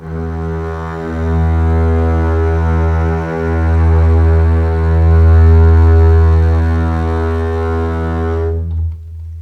E2 LEG MF  L.wav